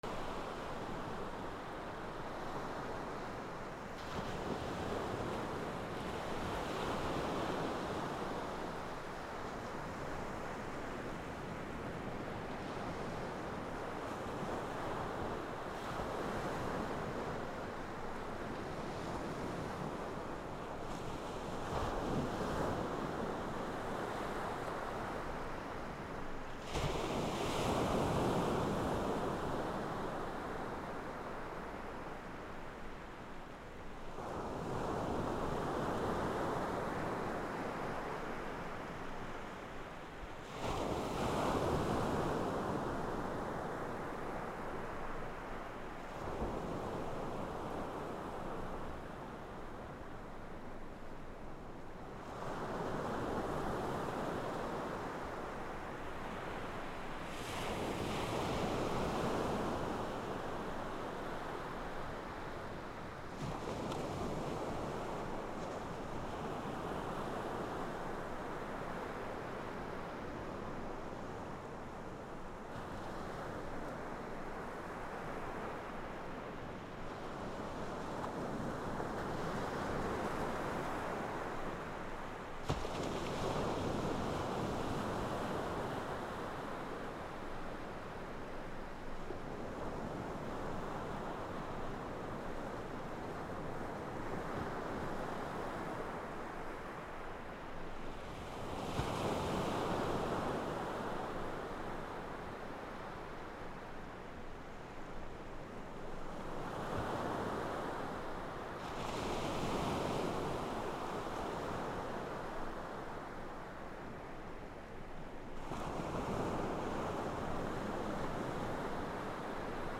波 約10m
/ B｜環境音(自然) / B-10 ｜波の音 / 波の音